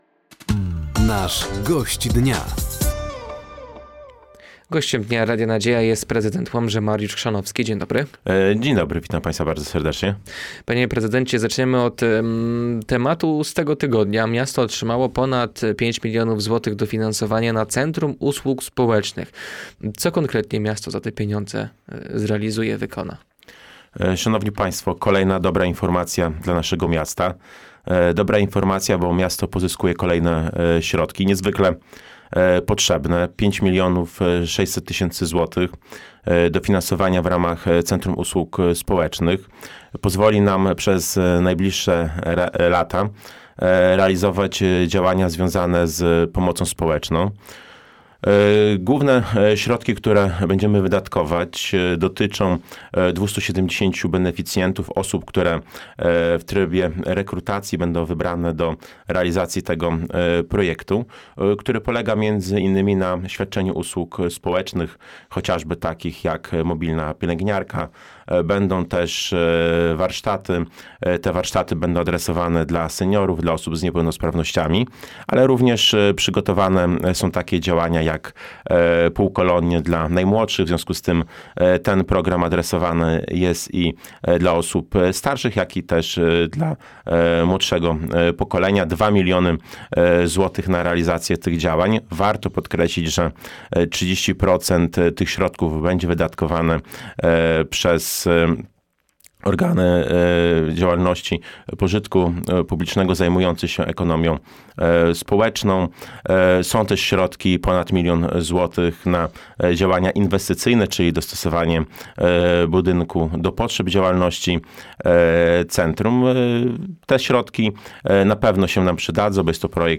Gościem Dnia Radia Nadzieja był prezydent Łomży Mariusz Chrzanowski. Tematem rozmowy było Centrum Usług Społecznych, rozpoczęta modernizacja infrastruktury w mieście oraz poruszona została kwestia powstania w mieście Centrum Integracji Cudzoziemców.